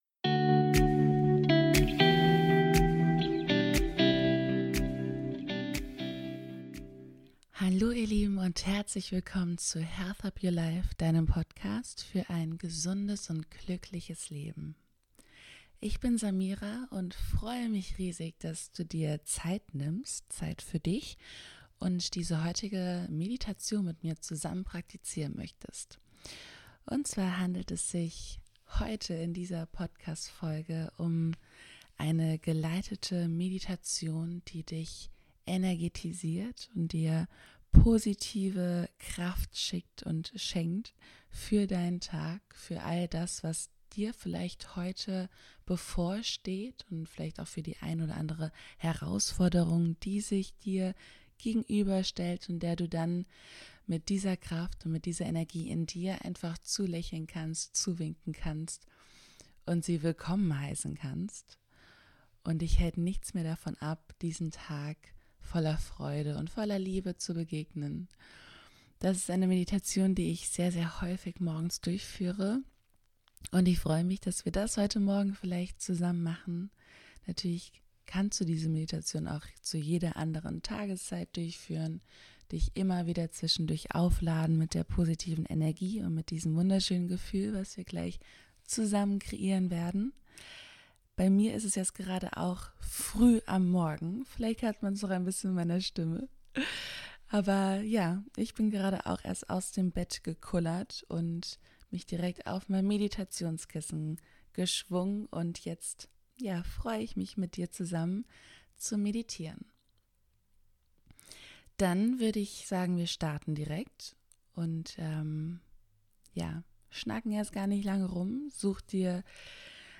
#013 Geführte Meditation für den perfekten Start in den Tag